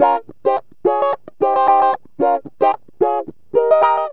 GTR 1 A#M110.wav